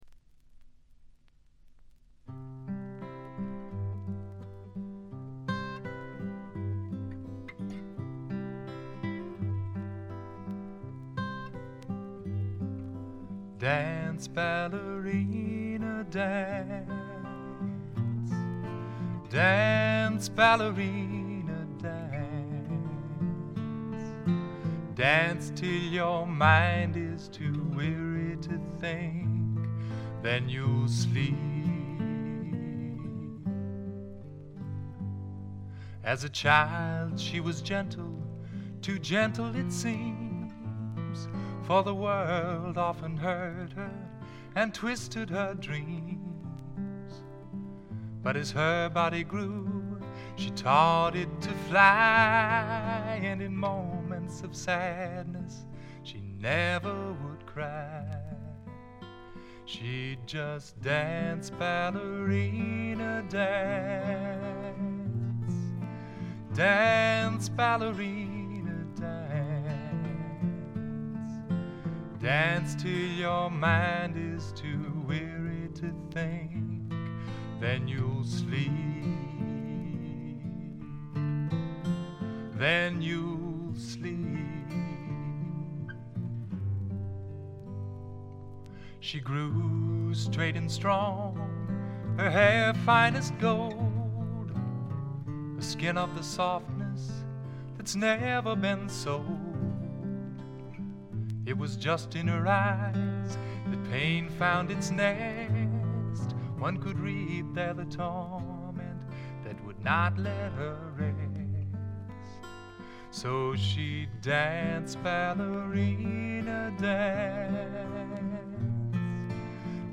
ごくわずかなノイズ感のみ。
シンプルなバックに支えられて、おだやかなヴォーカルと佳曲が並ぶ理想的なアルバム。
試聴曲は現品からの取り込み音源です。